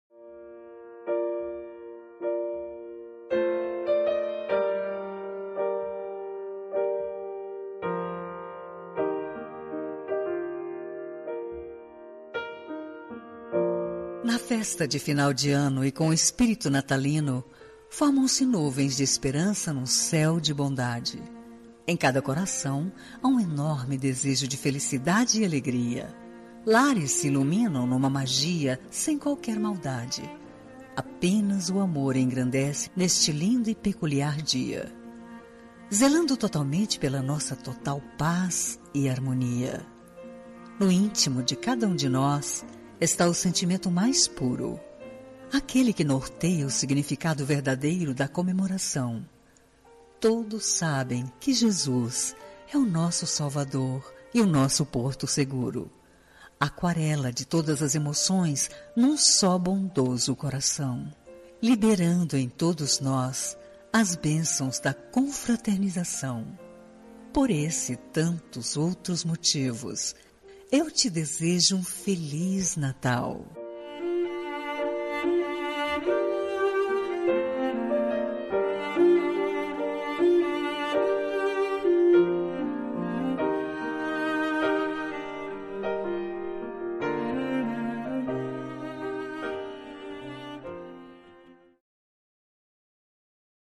Natal Pessoa Especial – Voz Feminina – Cód: 348971